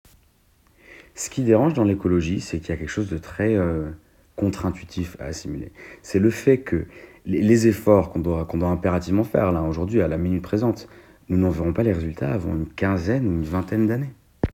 Voix anglaise
25 - 40 ans - Ténor